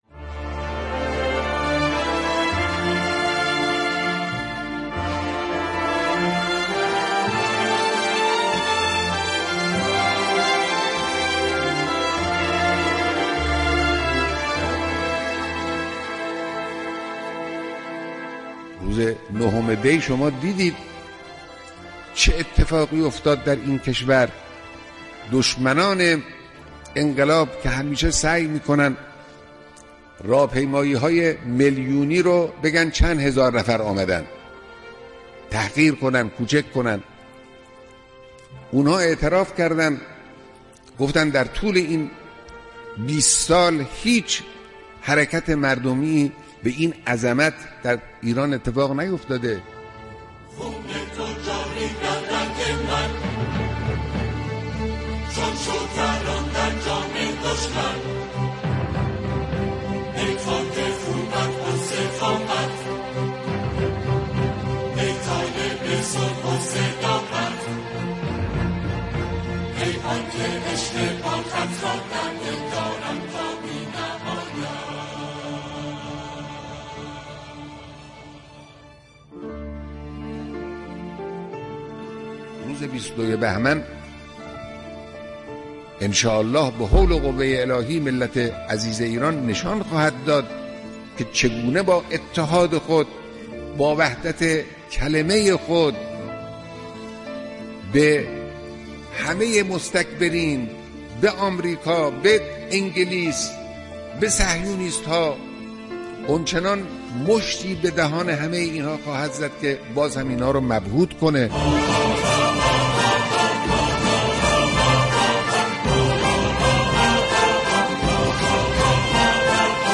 صوت کامل بیانات